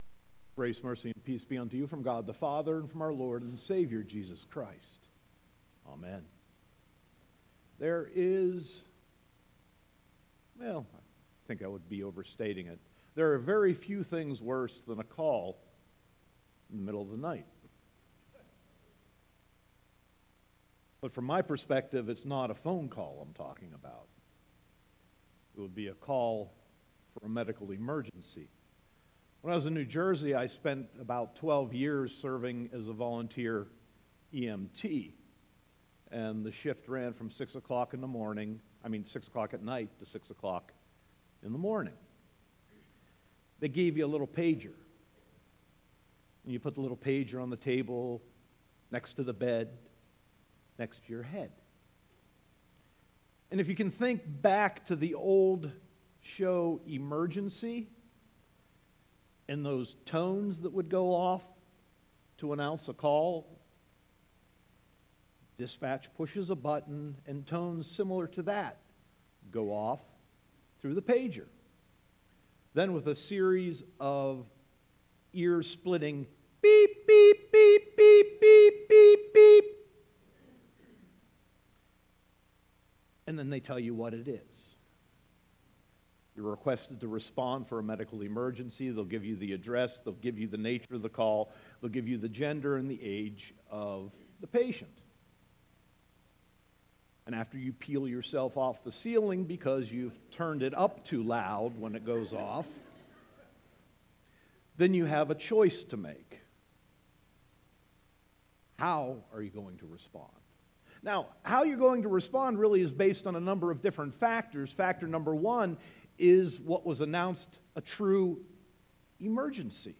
Sermon 1.21.2018